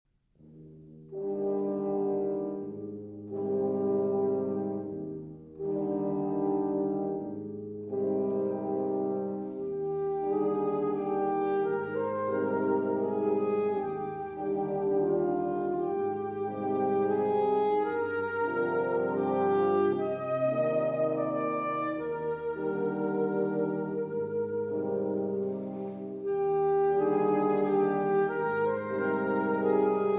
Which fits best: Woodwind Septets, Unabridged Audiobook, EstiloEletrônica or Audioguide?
Woodwind Septets